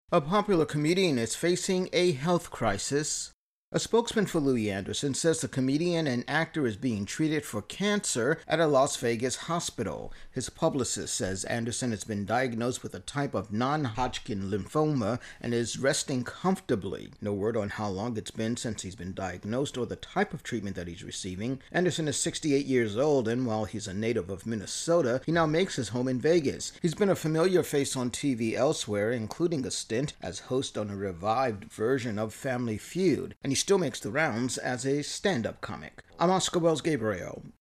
Intro+voicer on Louie Anderson being treated for cancer.